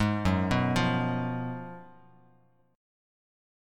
F#M7sus2sus4 Chord